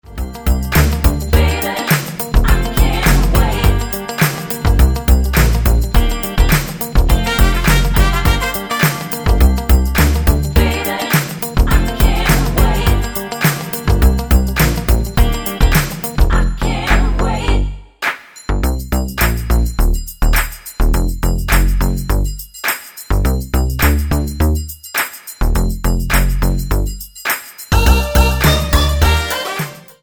Tonart:Gm mit Chor